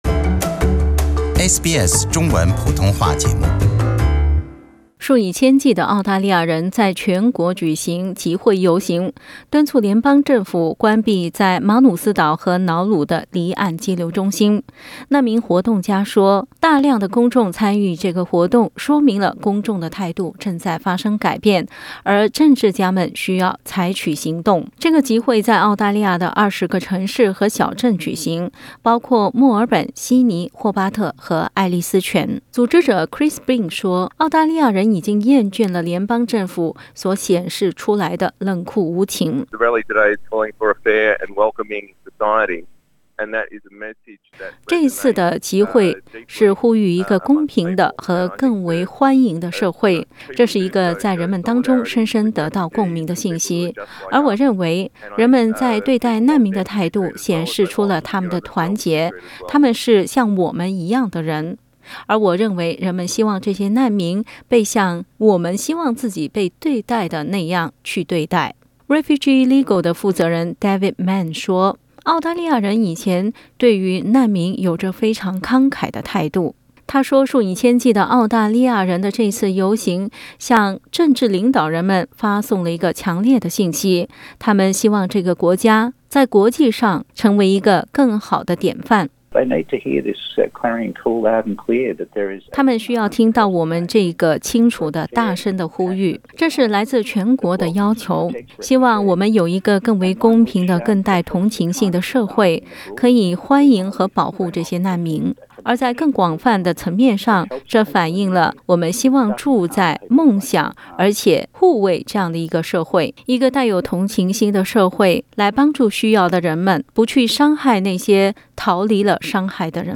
的报道